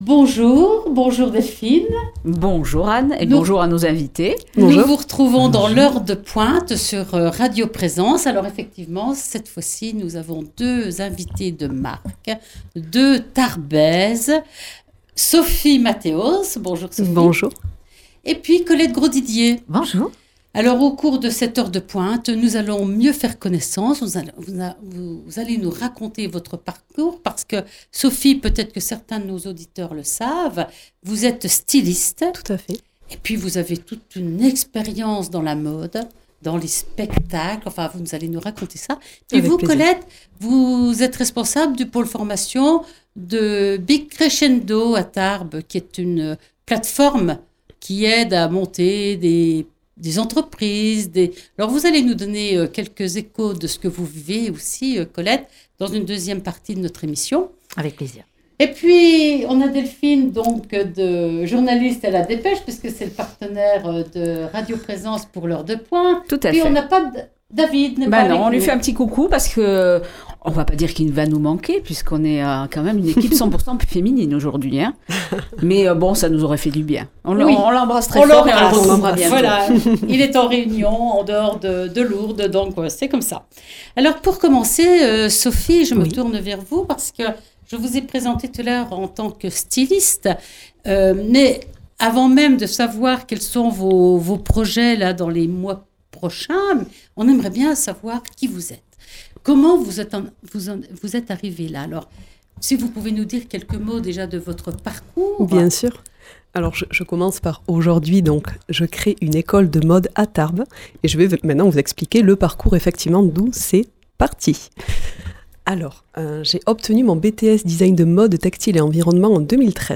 Dans l’heure de pointe sur Radio Présence, rencontre avec deux Tarbaises d’exception !